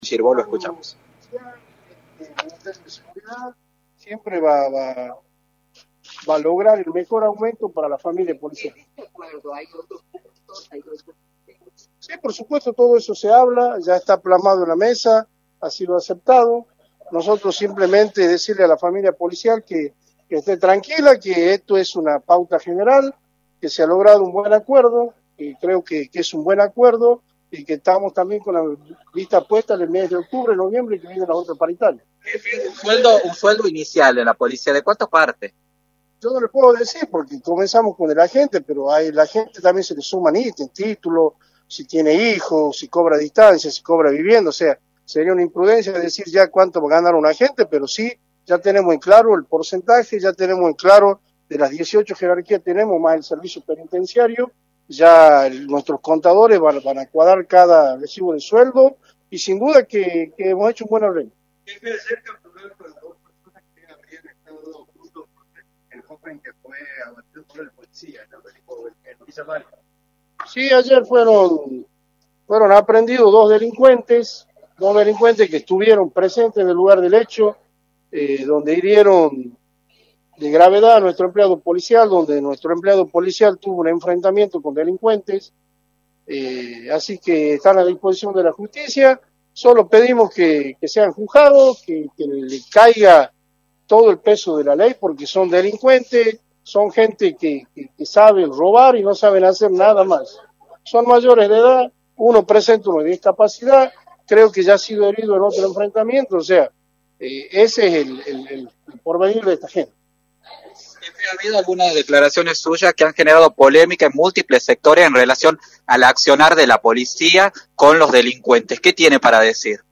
“El policía debe abatir a todo delincuente que se enfrente con la policía y le haga tiros, no hay polémicas con esto, lo tiene que abatir, esto es así porque si a nuestro agente le meten un tiro en la nuca, ¿Qué hacemos?, y esto no es un exceso porque a los excesos de los delincuentes ¿quién los ve?, entonces tenemos que velar por la persona de bien que está trabajando, no por el delincuente que mete un tiro y arruina una vida y una familia”, señaló Girvau en rueda de prensa.